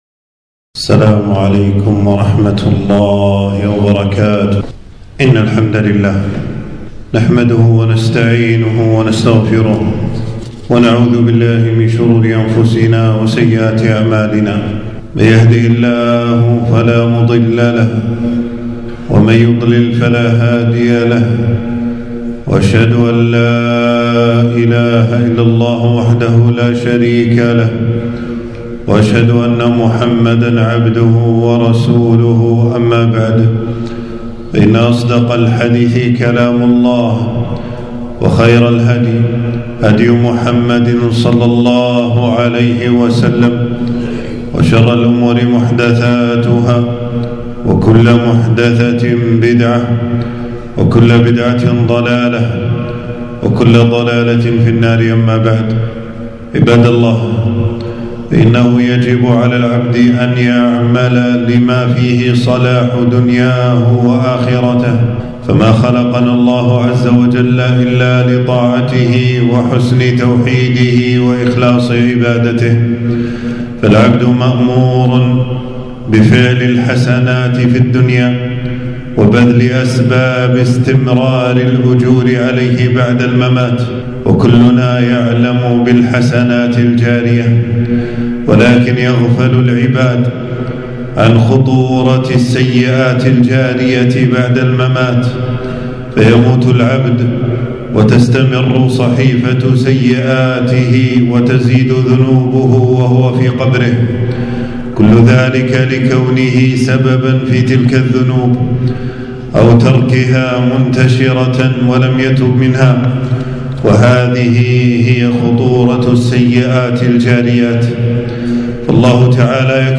تنزيل تنزيل التفريغ خطبة بعنوان: خطورة السيئات الجارية .
في مسجد السعيدي بالجهراء